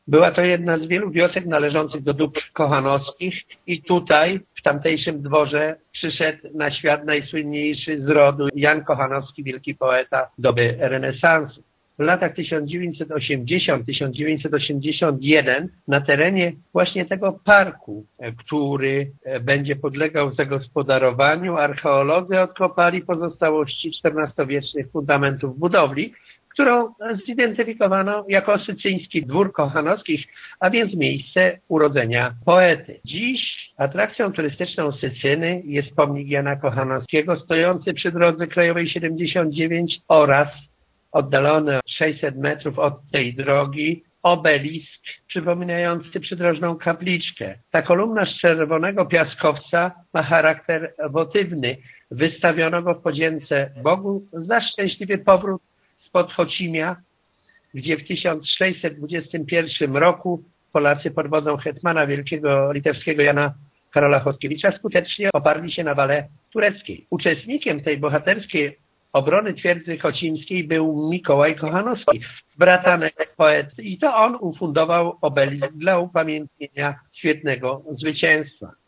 Włodzimierz Kabus przypomina, że zarówno Sycyna jak i sam park w tej miejscowości to szczególne miejsce na Ziemi Zwoleńskiej: